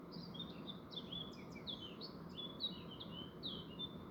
Hvilken fugl synger her?
I ukesvis har en morgenglad fugl sunget utenfor mitt bosted, uten at jeg har klart å se og identifisere den.
Sted; en dal nær Hardangervidda, i Telemark.
Hagesanger.